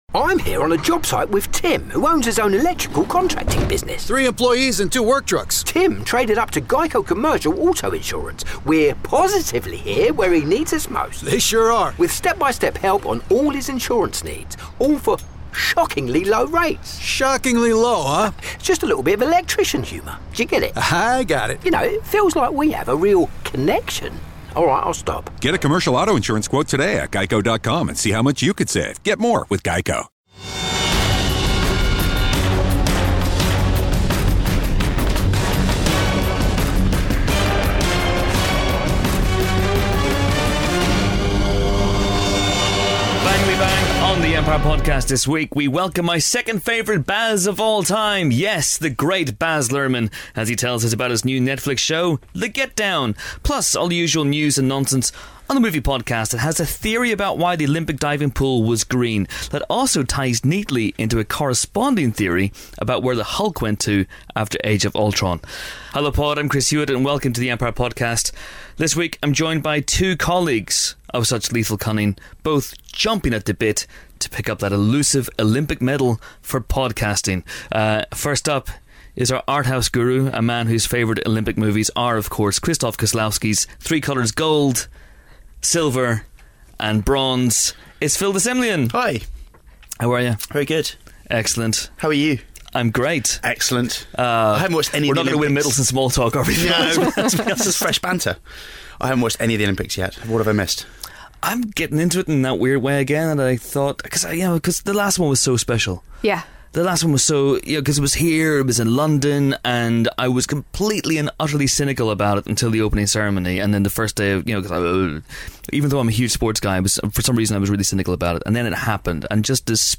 #224: Baz Luhrmann The Empire Film Podcast Bauer Media Tv & Film, Film Reviews 4.6 • 2.7K Ratings 🗓 12 August 2016 ⏱ 88 minutes 🔗 Recording | iTunes | RSS 🧾 Download transcript Summary Baz Luhrmann joins us in the pod booth this week to talk about his new Netflix show The Get Down. Plus, we discuss the best beach scenes in movies.